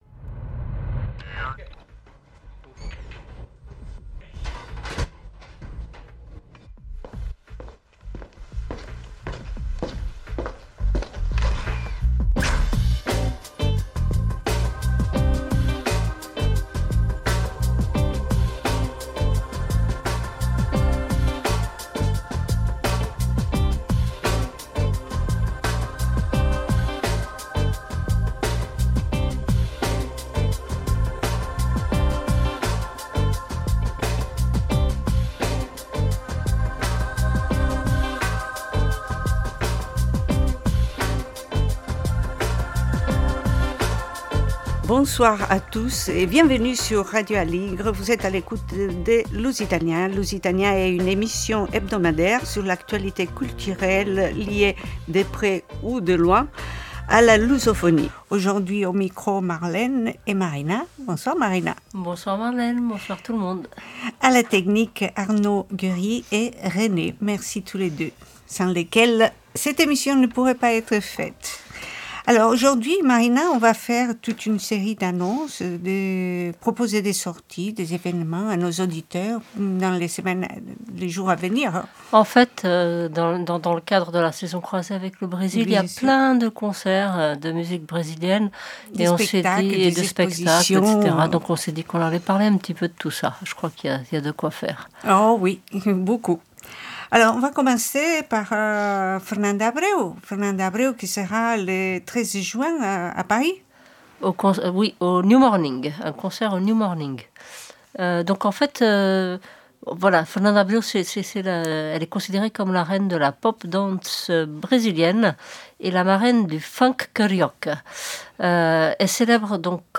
Une émission pour vous inviter à sortir, avec leur accompagnement musical :